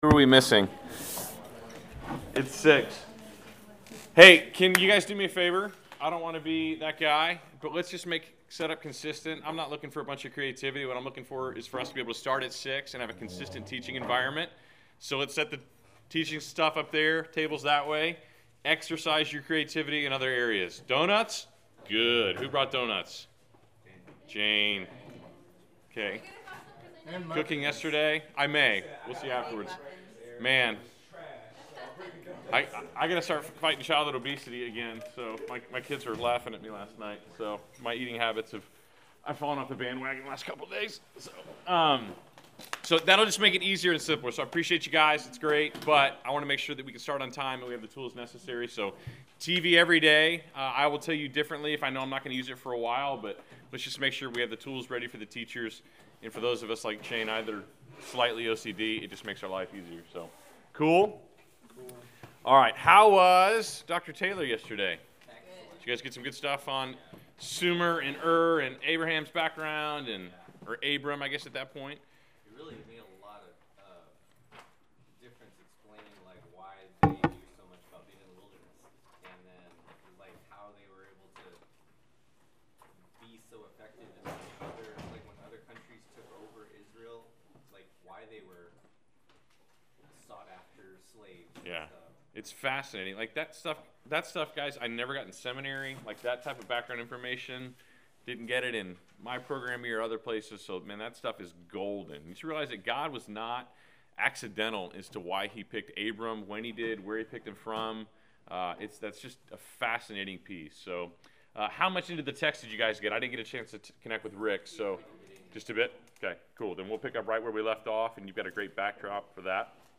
Class Session Audio September 23